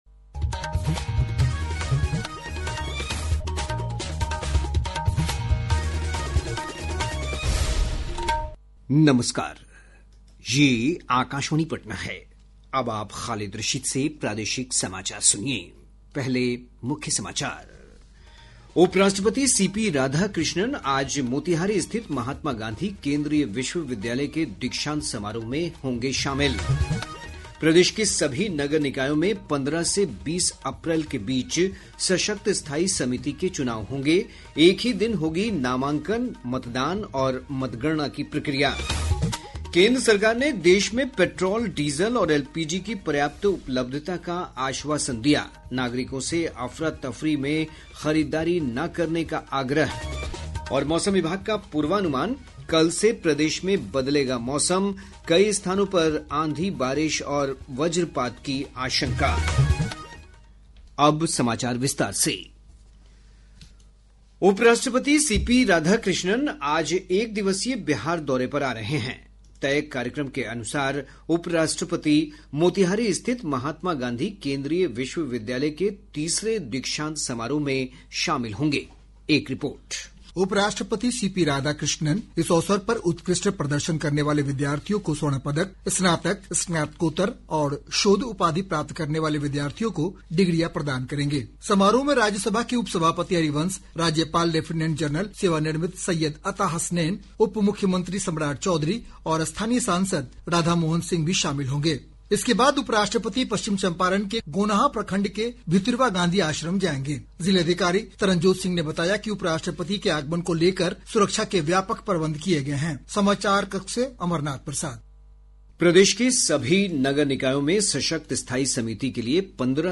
Transcript summary Play Audio Evening News